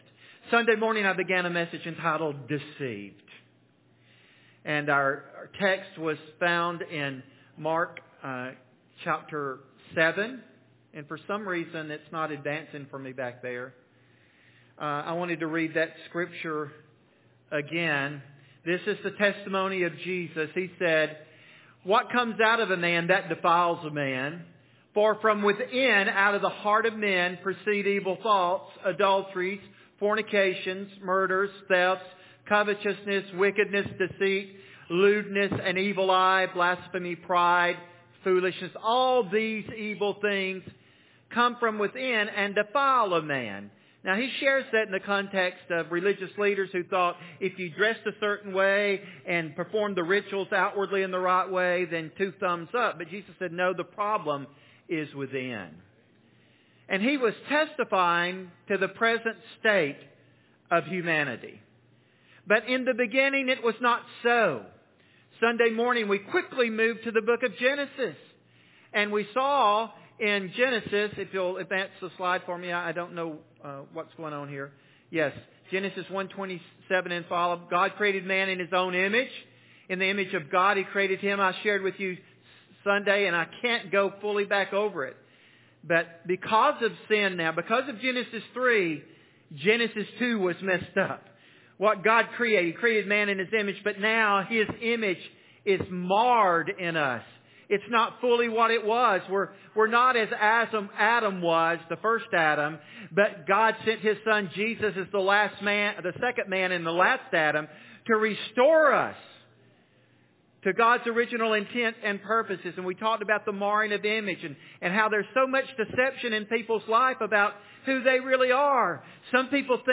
Deceived Current Sermon Deceived (Part 2) Beware! The world will tell you what it wants to sell you